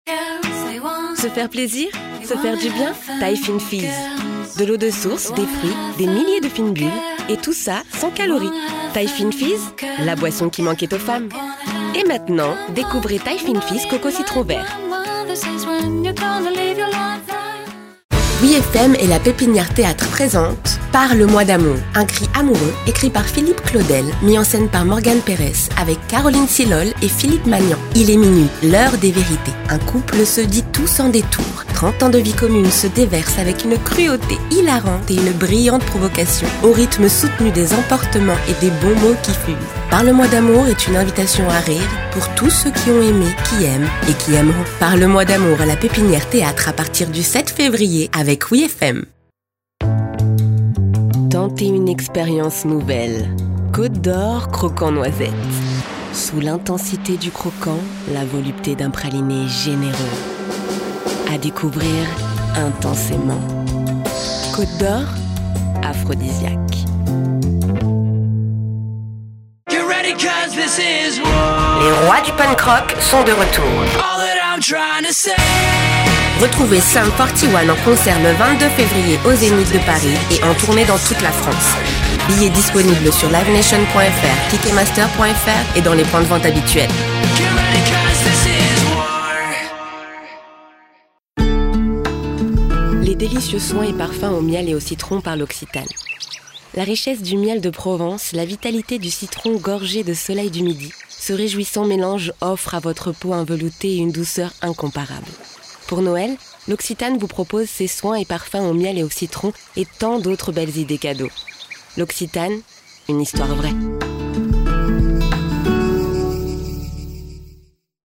Voix off
Démo voix pub
- Mezzo-soprano